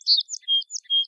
Sparrow 003.wav